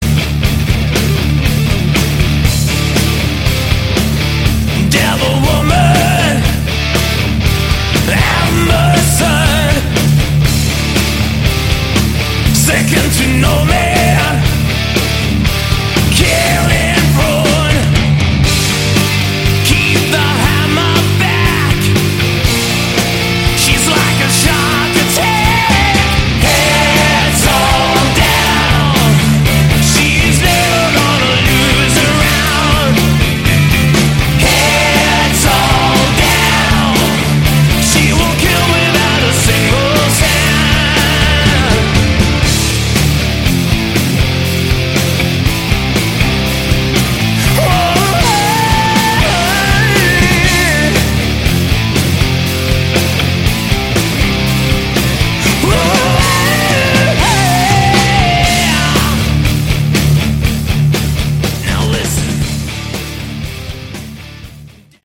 Category: Hard Rock
vocals
bass
keyboards
guitar
drums